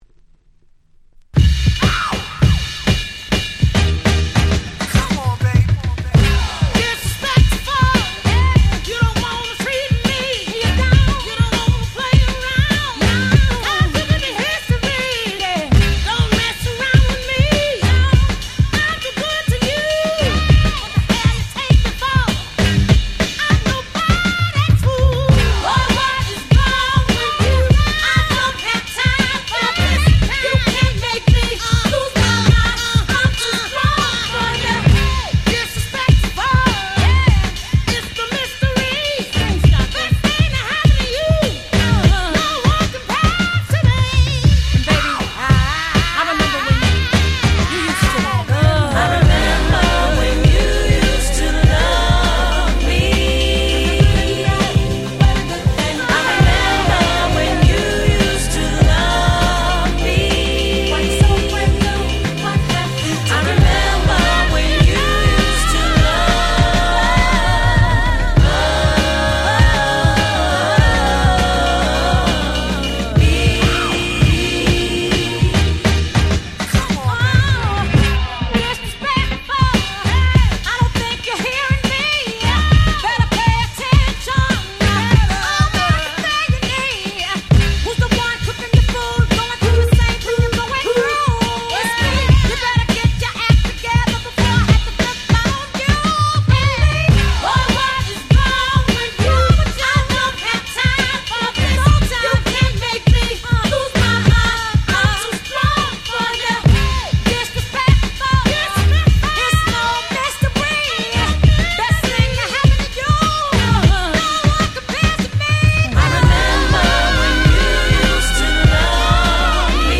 07' Nice R&B / Funk !!